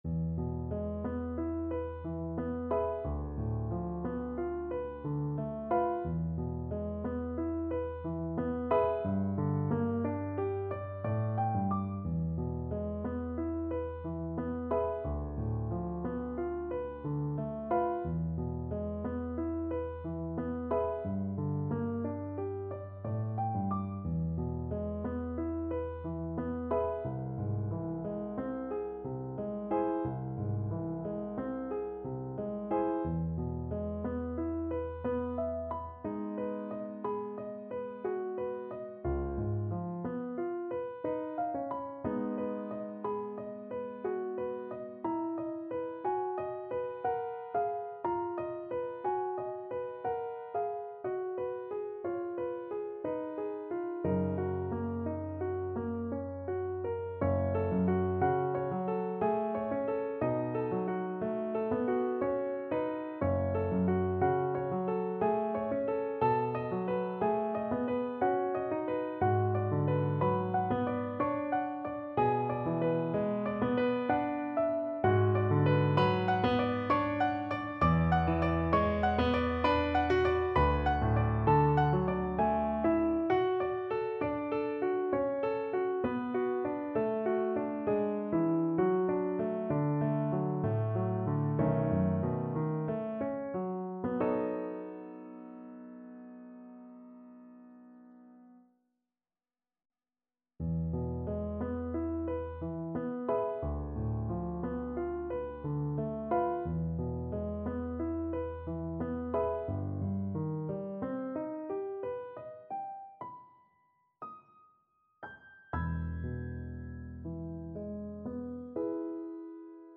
Play (or use space bar on your keyboard) Pause Music Playalong - Piano Accompaniment Playalong Band Accompaniment not yet available reset tempo print settings full screen
E major (Sounding Pitch) (View more E major Music for Viola )
3/4 (View more 3/4 Music)
Andante ma non troppo =60
Classical (View more Classical Viola Music)